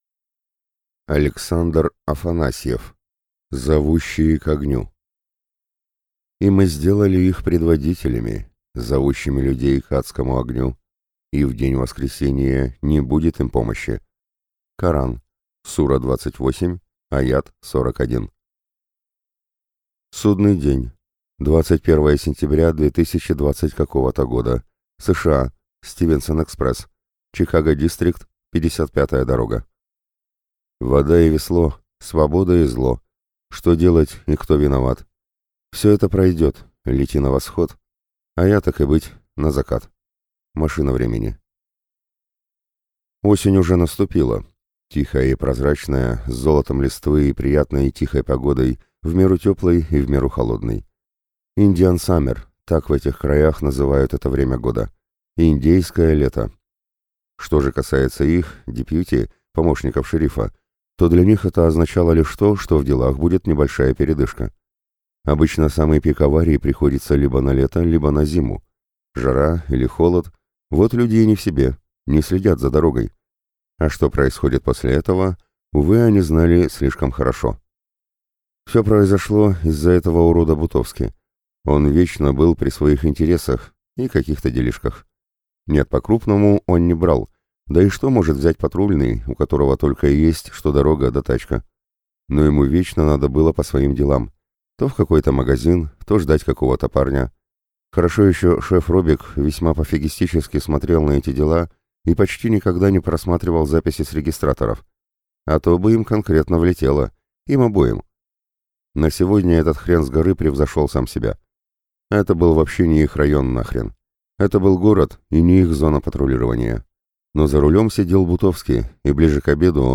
Аудиокнига Зовущие к огню | Библиотека аудиокниг
Прослушать и бесплатно скачать фрагмент аудиокниги